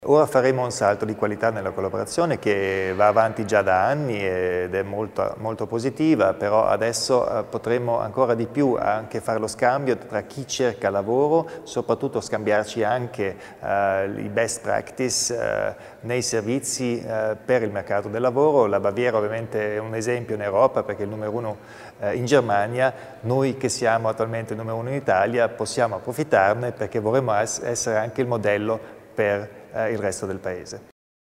L'Assessore Stocker spiega i dettagli dell'accordo tra Provincia e Baviera